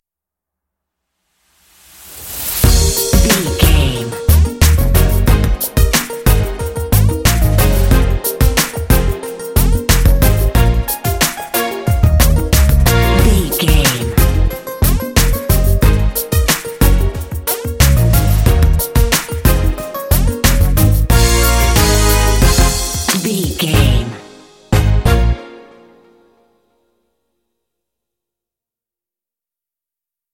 Aeolian/Minor
B♭
cool
futuristic
synthesiser
bass guitar
drums
strings
synth-pop